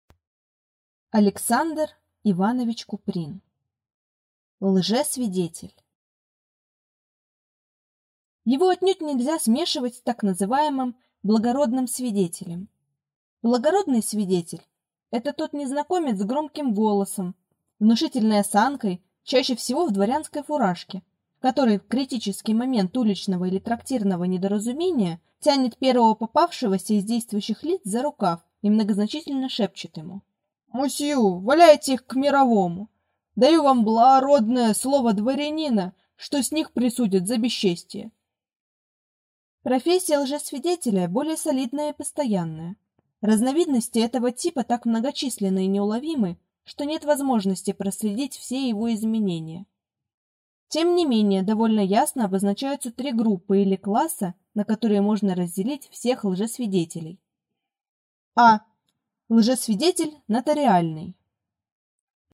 Аудиокнига Лжесвидетель | Библиотека аудиокниг